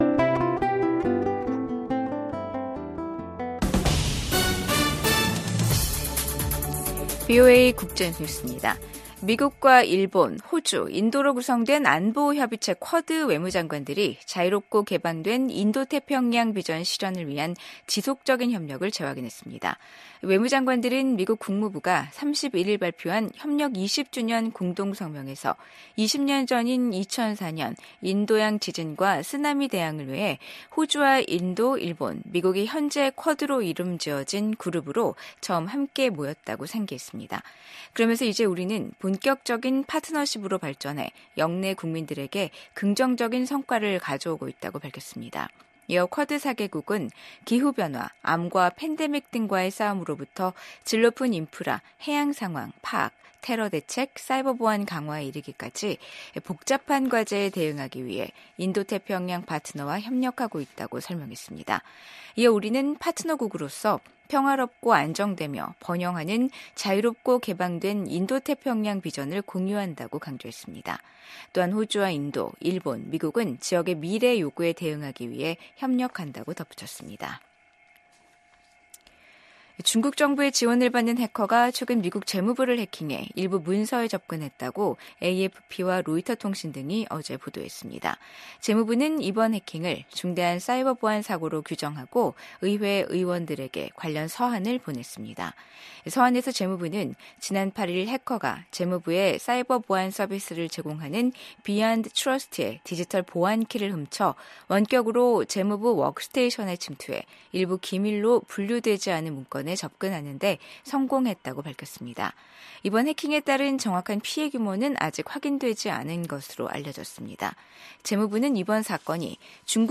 VOA 한국어 간판 뉴스 프로그램 '뉴스 투데이', 2024년 12월 31일 3부 방송입니다. 미국 국방부는 러시아에 파병된 북한군이 쿠르스크에서 벌이는 공격이 별로 효과가 없다고 평가했습니다.